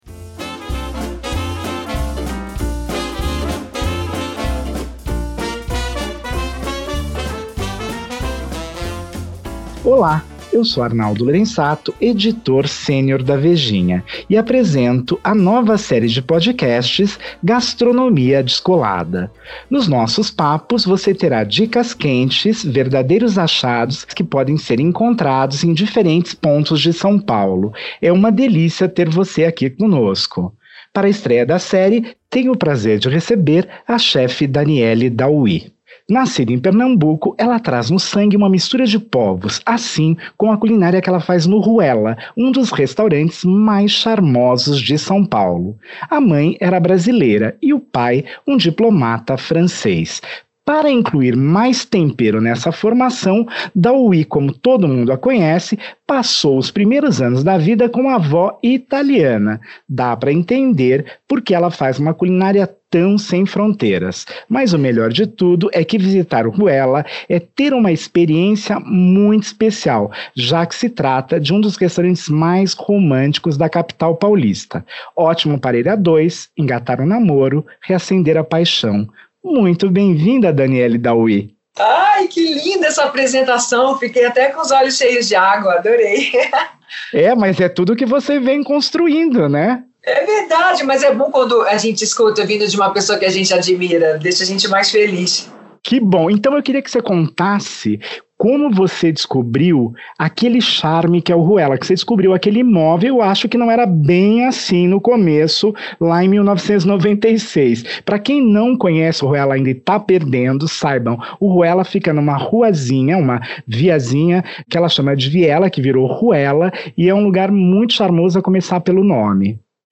Minha conversa para a estreia do novo podcast é com a chef e sócia do Ruella, um dos restaurantes mais românticos de São Paulo